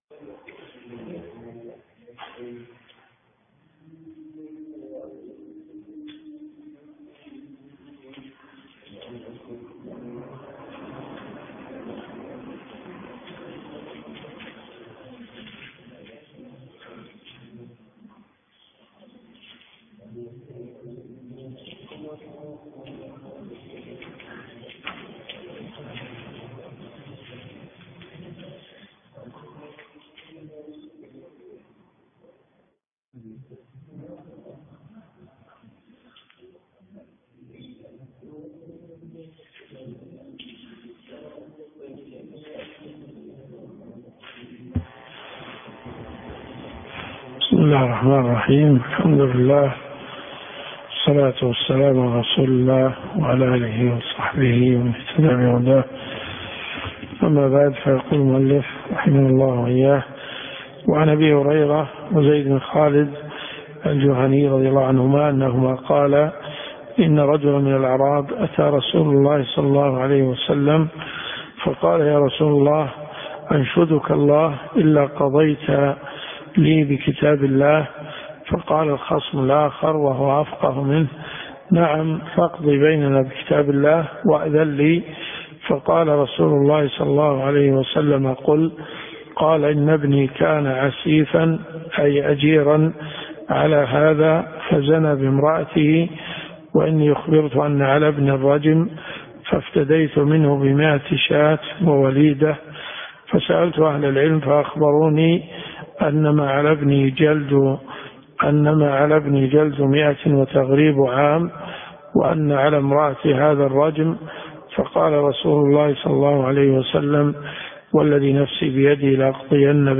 الرئيسية الكتب المسموعة [ قسم الحديث ] > صحيح مسلم .